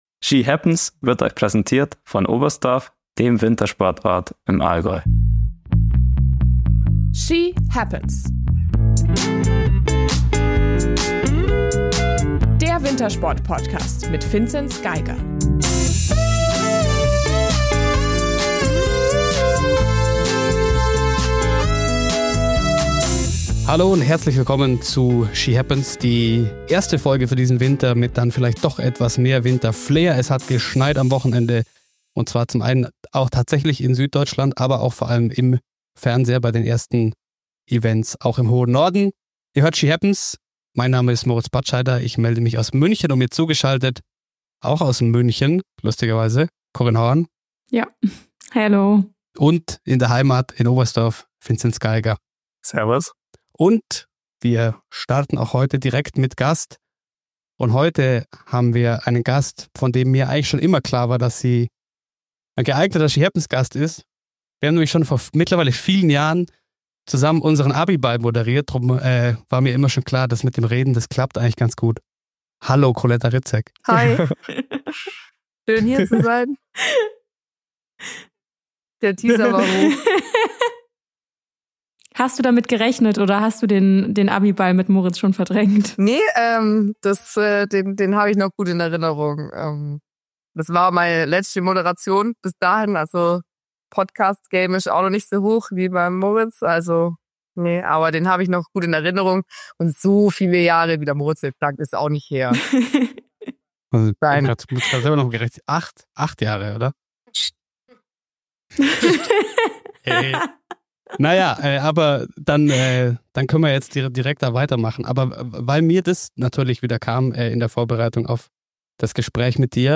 Vor dem Ruka-Wochenende sprechen wir mit der DSV-Top-Sprinterin.